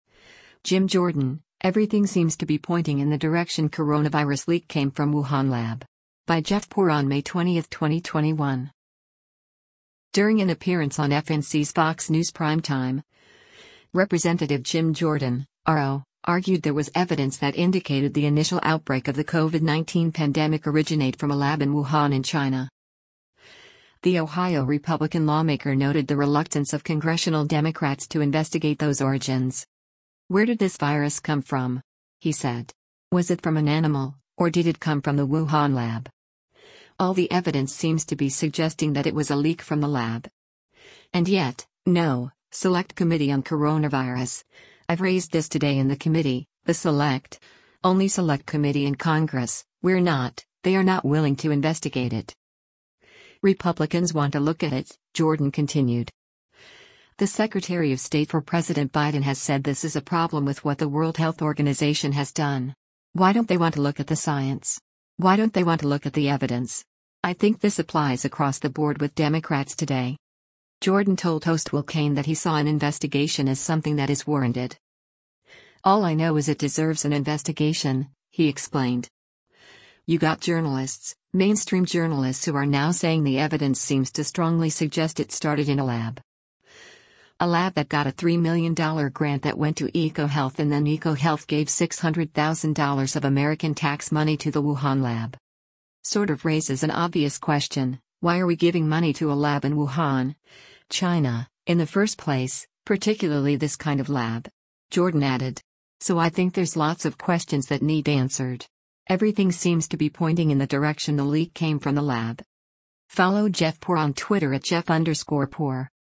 During an appearance on FNC’s “Fox News Primetime,” Rep. Jim Jordan (R-OH) argued there was evidence that indicated the initial outbreak of the COVID-19 pandemic originate from a lab in Wuhan in China.
Jordan told host Will Cain that he saw an investigation as something that is warranted.